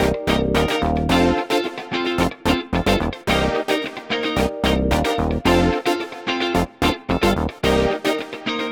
30 Backing PT2.wav